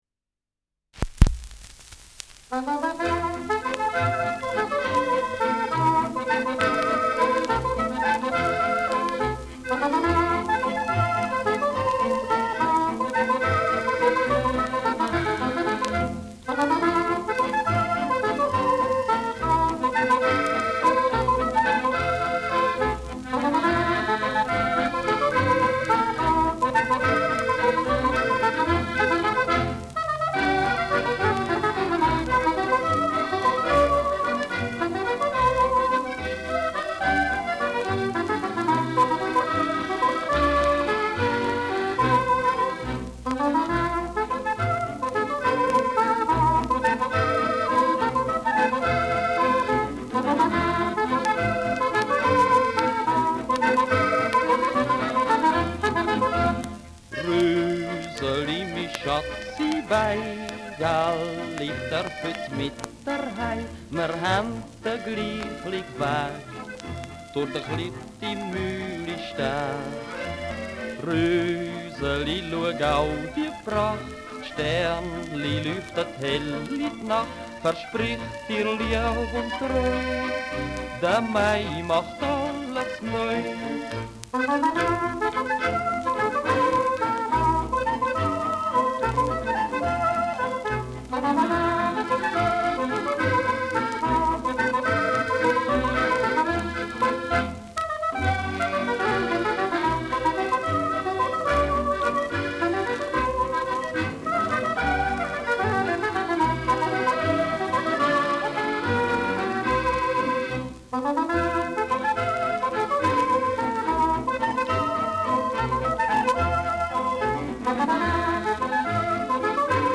Walzer
Schellackplatte
mit Gesang
Ländlerkapelle* FVS-00018